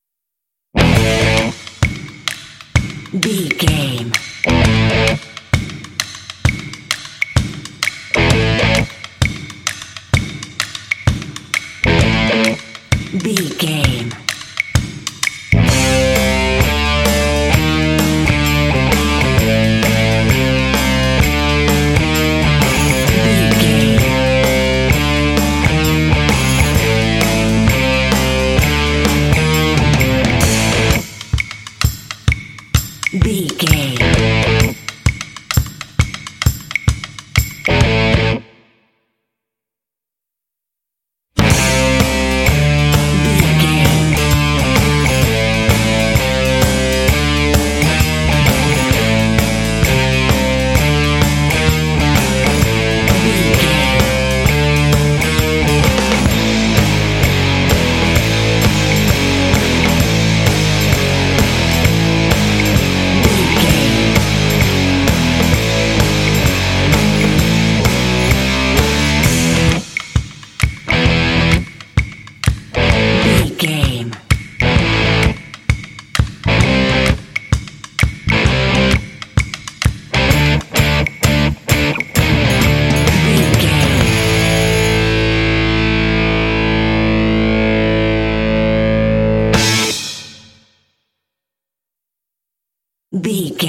This groovy track is ideal for action and sports games.
Aeolian/Minor
groovy
electric guitar
percussion
drums
bass guitar
rock
classic rock